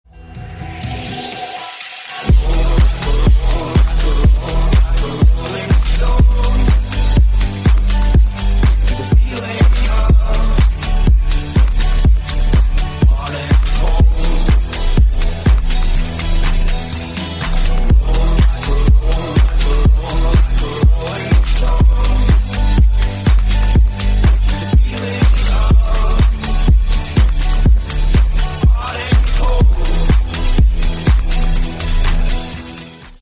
Громкие Рингтоны С Басами
Рингтоны Ремиксы » # Танцевальные Рингтоны